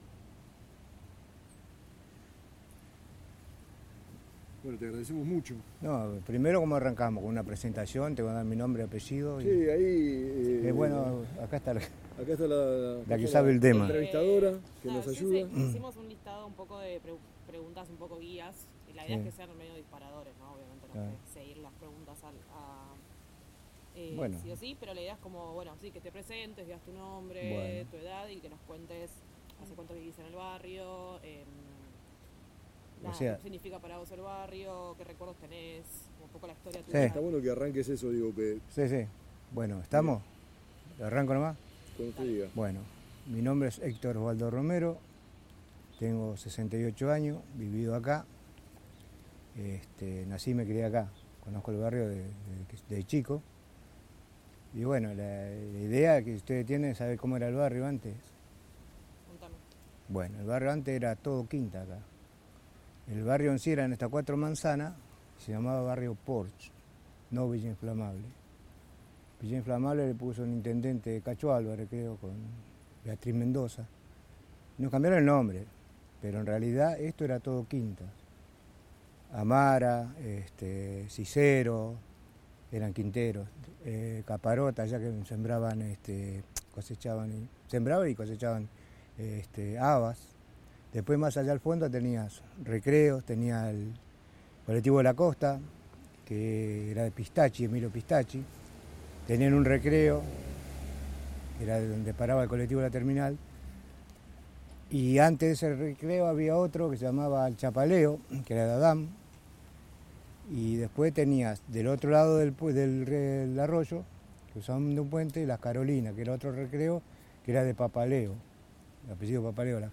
1 grabación sonora en soporte magnético